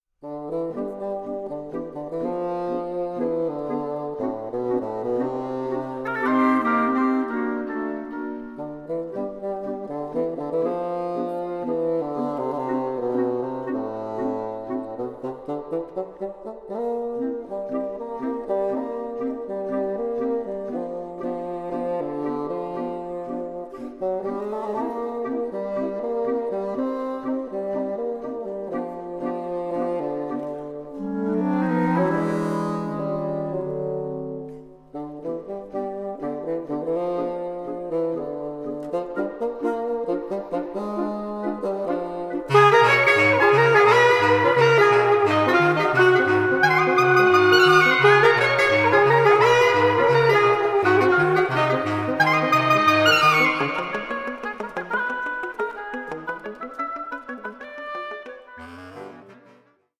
reed players